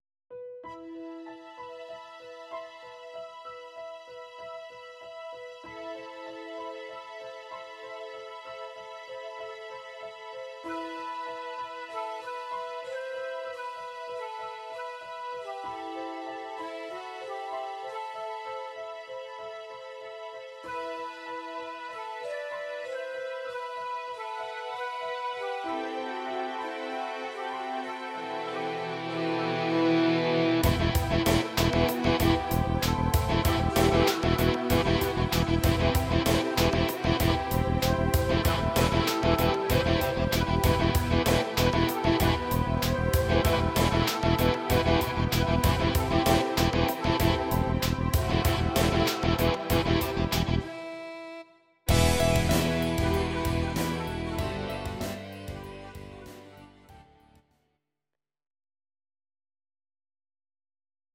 These are MP3 versions of our MIDI file catalogue.
Your-Mix: Rock (2958)